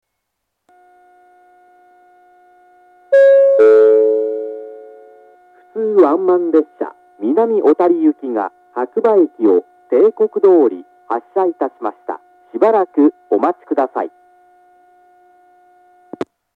２番線白馬駅発車案内放送 普通ワンマン南小谷行の放送です。